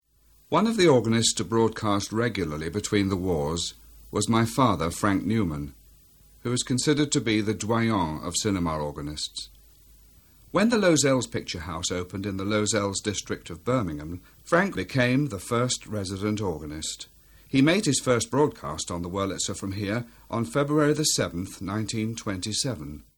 More Organ recordings